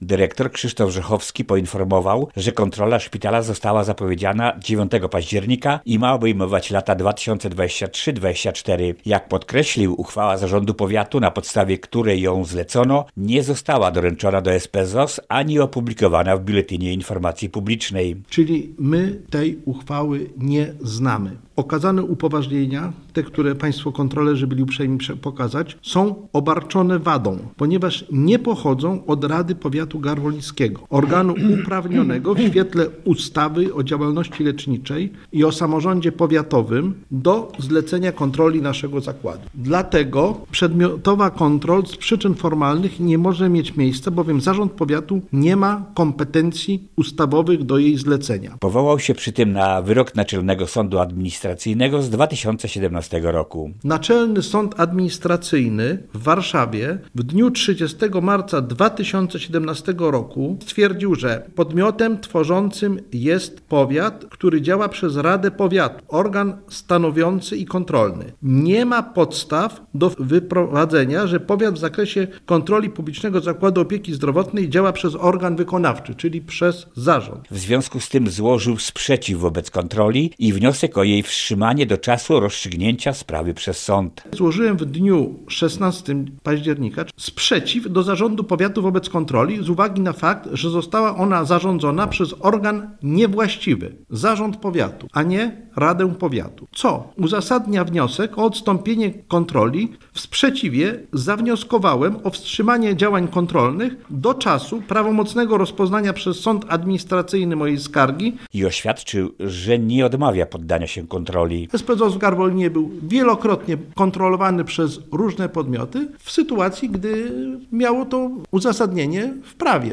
konferencja.mp3